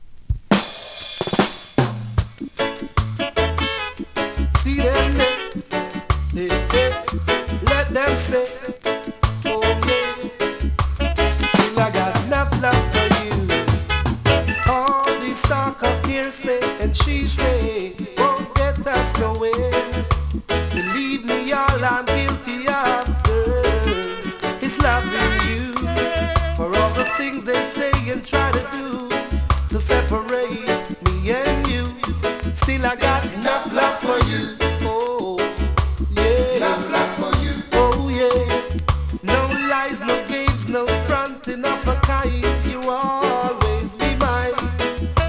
DANCEHALL!!
スリキズ、ノイズかなり少なめの